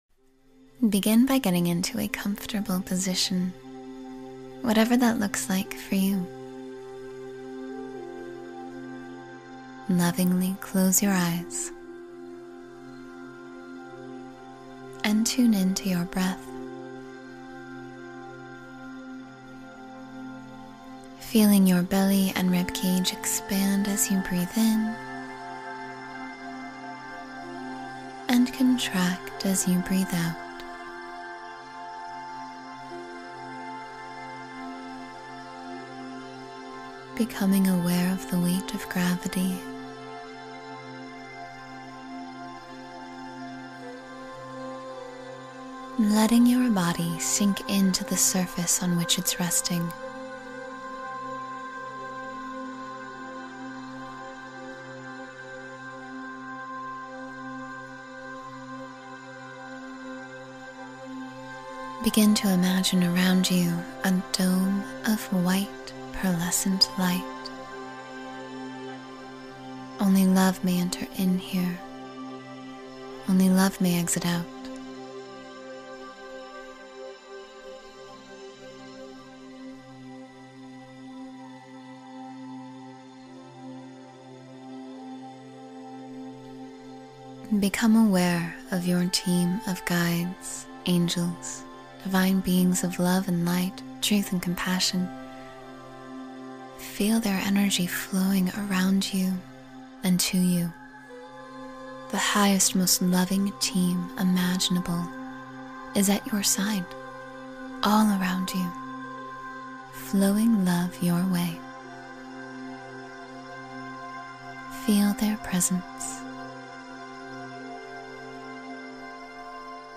A Meditation Filled with Divine Love — 10-Minute Heart Awakening Practice